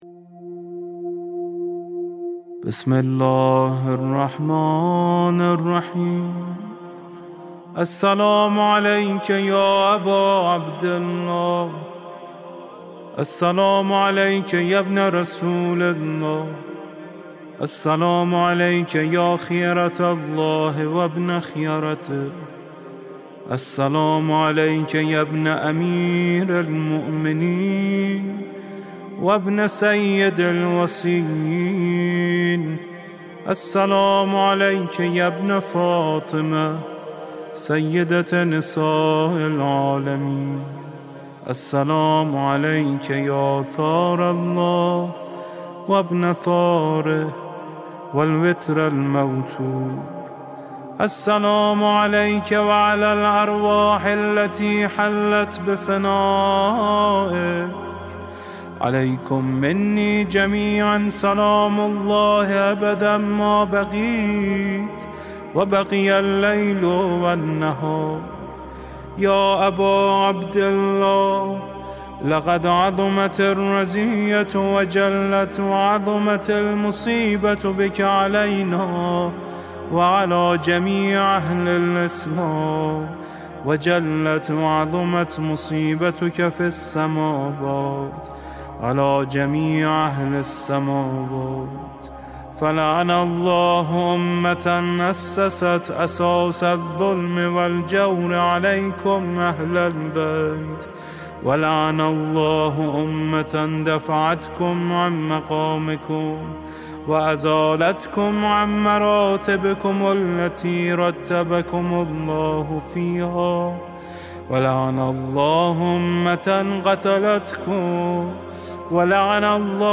صوت/ قرائت زیارت عاشورا با صدای علی فانی